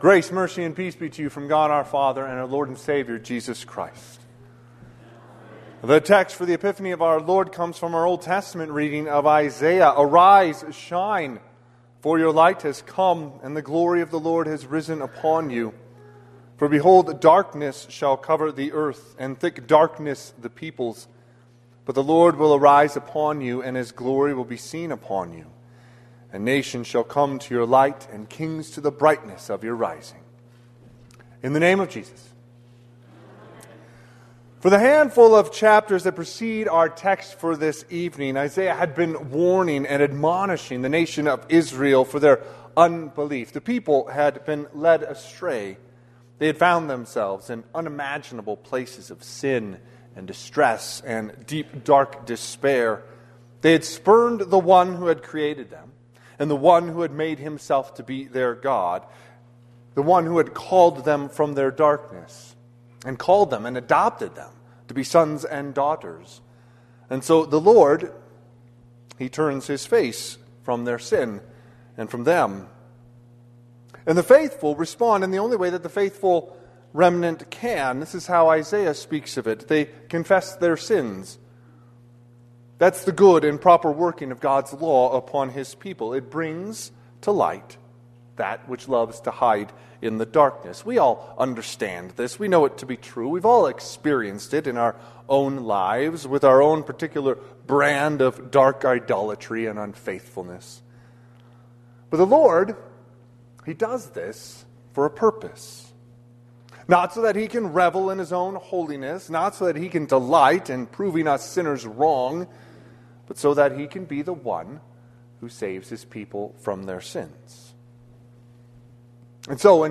Sermon – 1/6/2026
Sermon_Epiphany_Jan6_2026.mp3